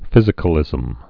(fĭzĭ-kə-lĭzəm)